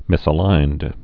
(mĭsə-līnd)